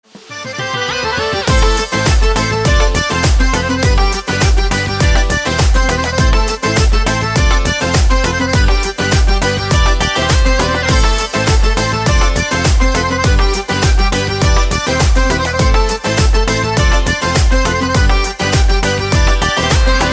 громкие
без слов
аккордеон
кавказские
шансон
кавказская музыка
Кавказские мотивы